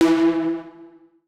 Universal UI SFX / Clicks
UIClick_Ugly Distortion 01.wav